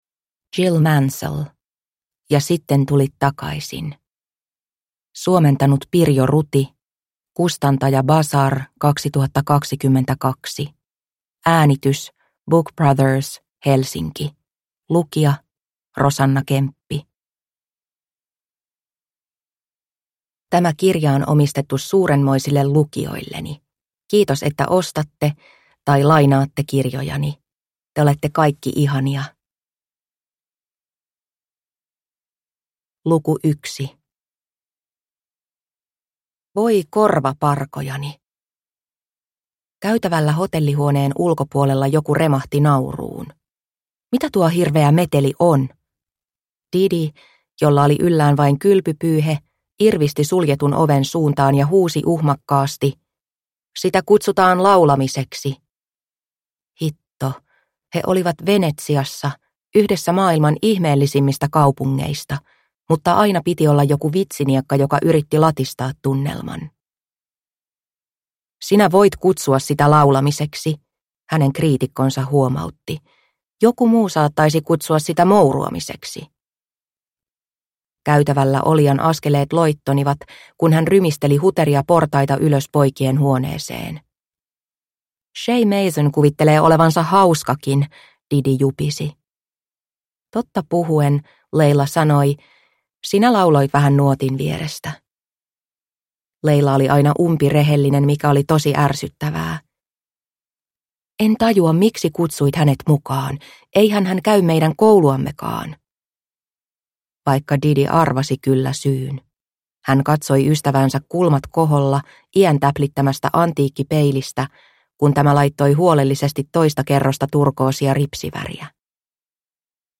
Ja sitten tulit takaisin – Ljudbok – Laddas ner